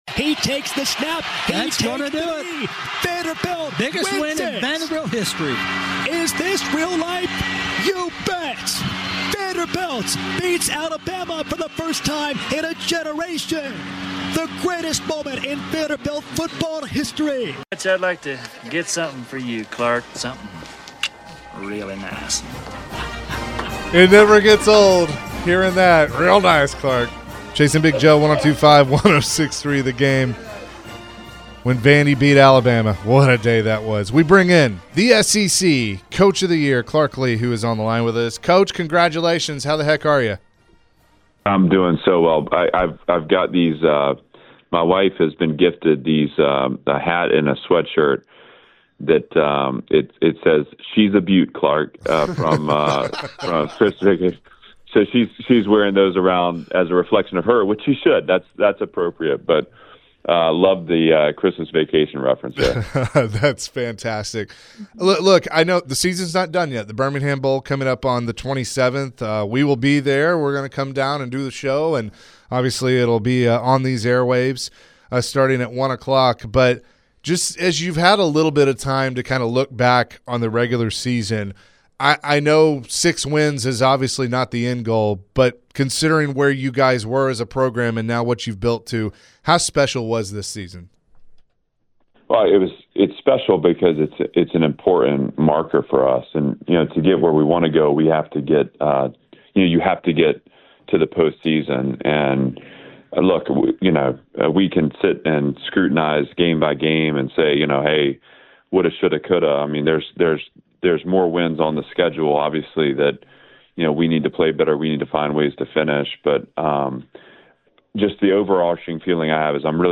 Vanderbilt head coach Clark Lea joined the show as he was recently named SEC Coach of the Year. Clark discussed how his team proved a lot of people wrong. Clark also mentioned what the future looks like for the program.